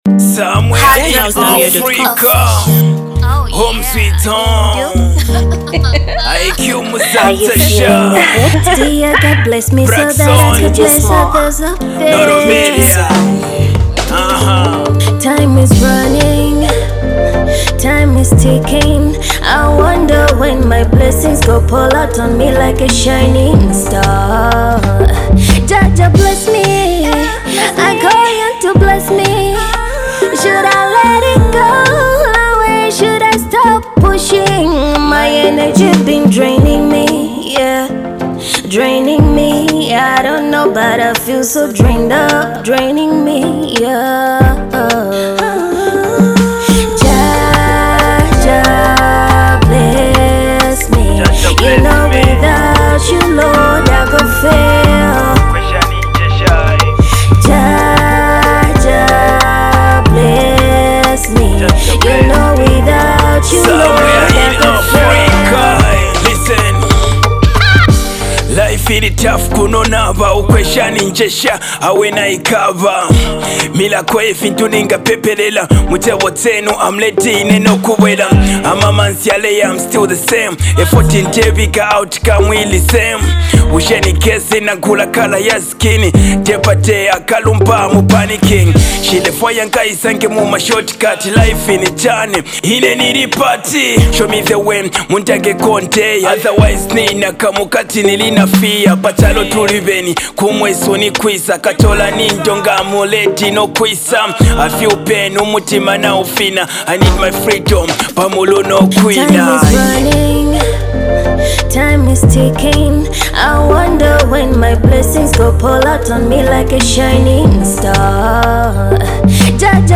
a soulful track